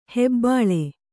♪ hebbāḷe